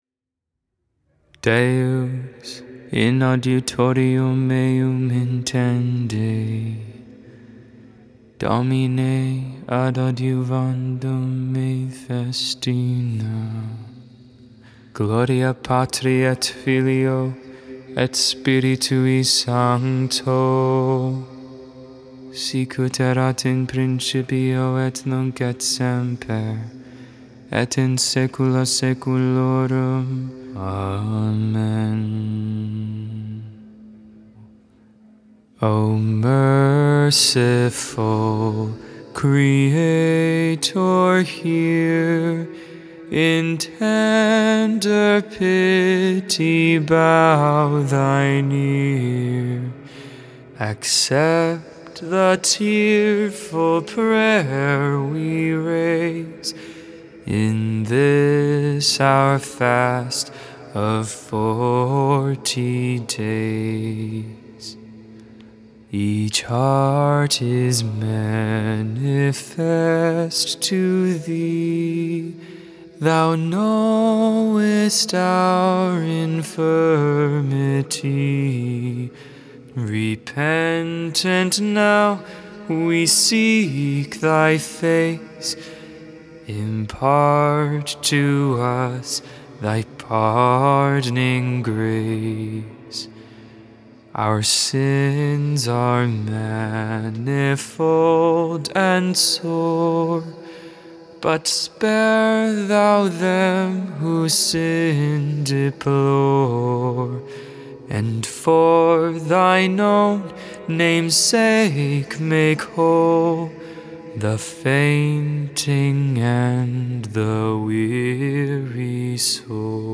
4.3.22 Vespers, Sunday Evening Prayer